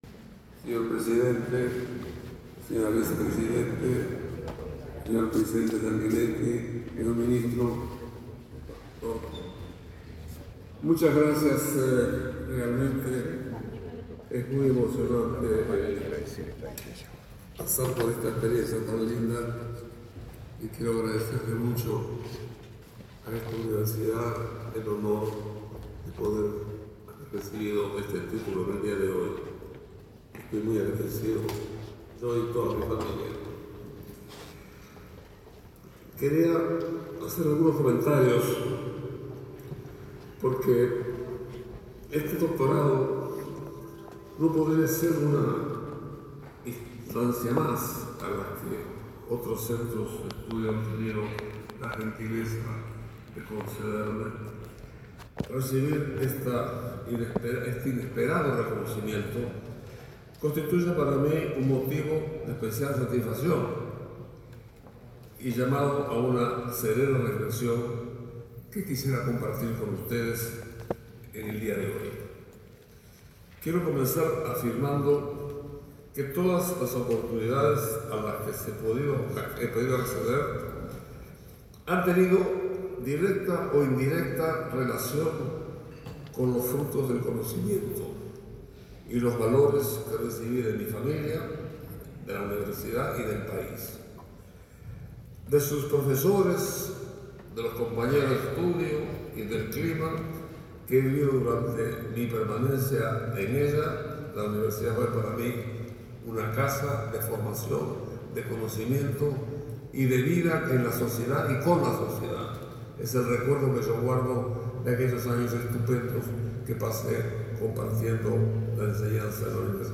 Palabras de Enrique Iglesias 27/08/2025 Compartir Facebook X Copiar enlace WhatsApp LinkedIn Enrique Iglesias recibió la distinción de doctor honoris causa otorgada por la Universidad Católica del Uruguay, ceremonia en la que participó el presidente de la República, Yamandú Orsi.